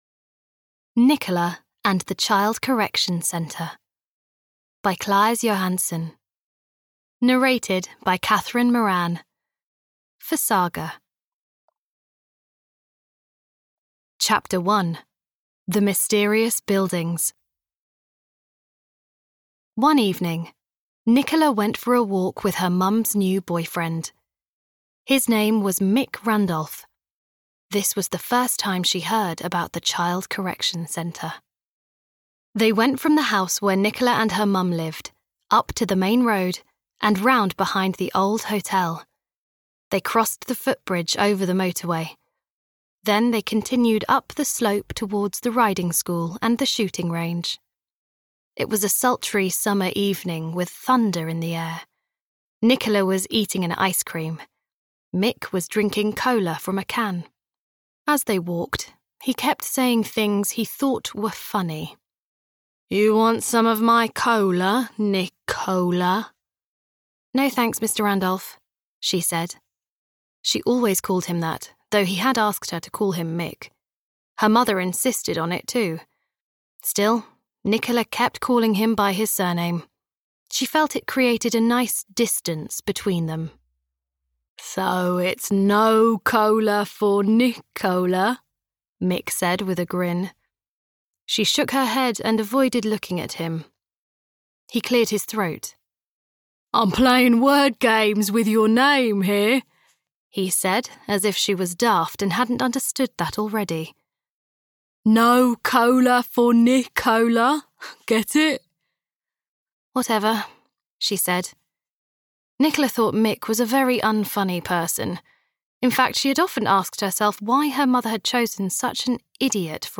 Nicola and the Child Correction Centre (EN) audiokniha
Ukázka z knihy